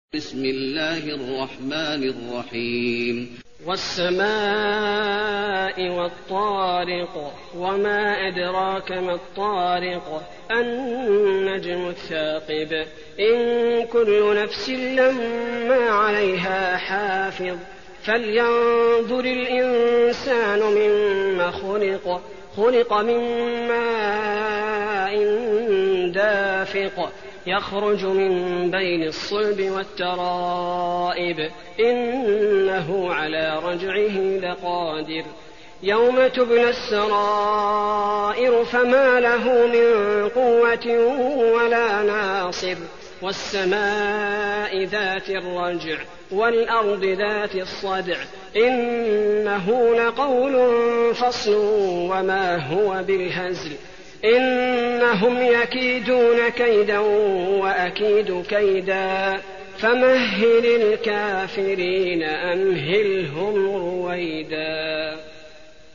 المكان: المسجد النبوي الطارق